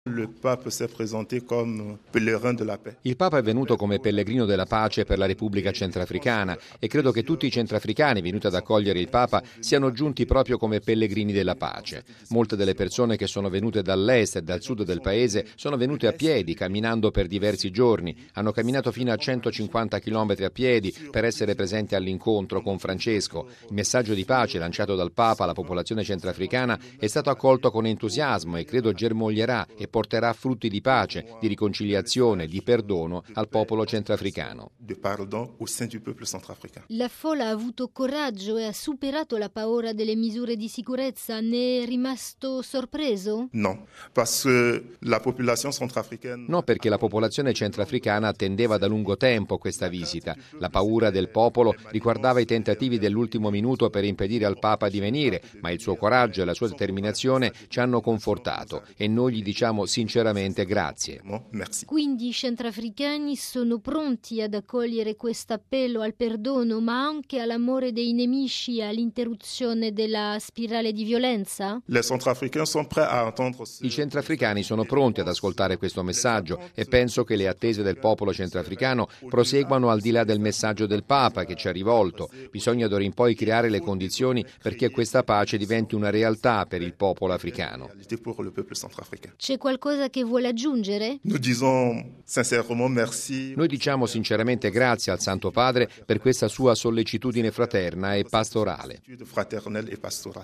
Con queste parole, il vicepresidente dei vescovi del Centrafrica, mons. Nestor Nongo Aziagbia, commenta la visita di Francesco nel suo Paese.